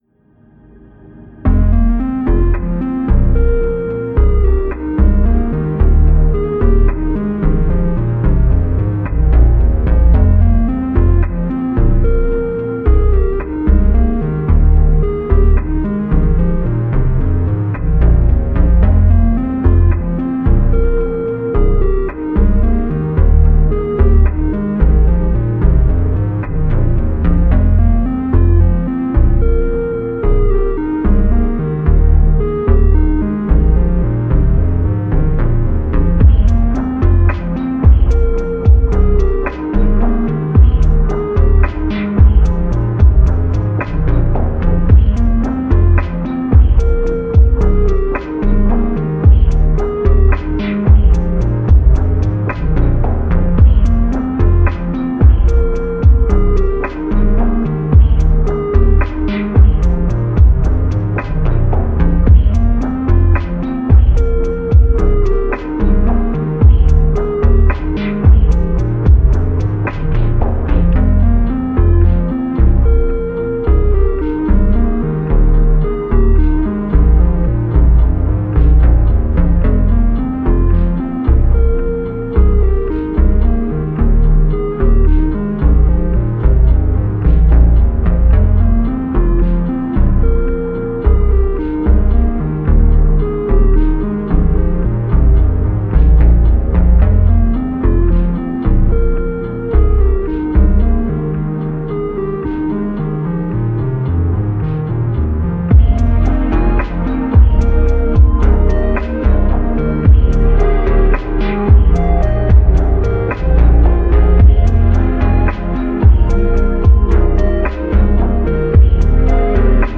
Index of /Music/recovered/vaporwave/